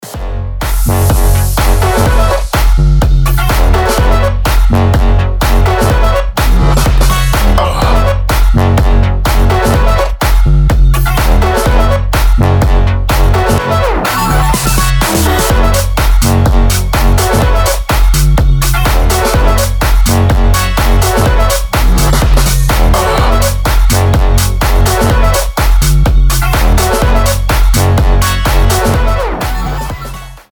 • Качество: 320, Stereo
ритмичные
громкие
Electronic
EDM
чувственные
Bass House
Чувственный бас-хаус